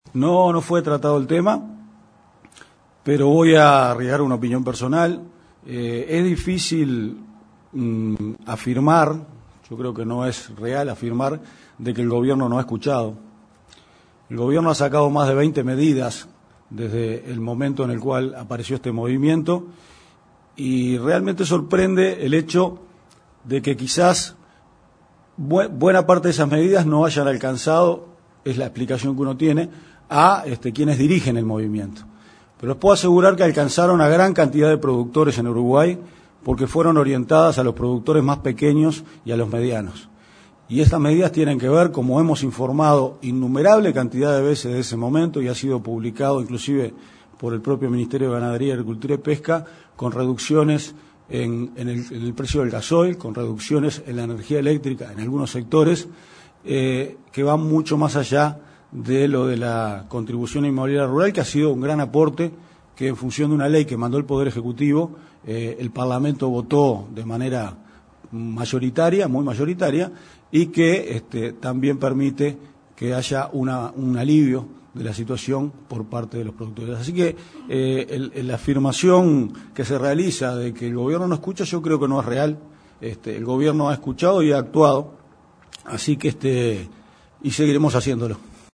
“Afirmar que el Gobierno no escucha no es real, el Gobierno ha escuchado y ha actuado”, reafirmó el director de OPP, Álvaro García, consultado por la prensa sobre los reclamos de los “autoconvocados”. Recordó que se han adoptado más de 20 medidas y dijo que quizás buena parte de ellas, como la baja del gasoil y la electricidad, no ha alcanzado a quienes dirigen el movimiento, pero sí a pequeños y medianos productores.